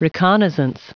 Prononciation du mot reconnaissance en anglais (fichier audio)
Prononciation du mot : reconnaissance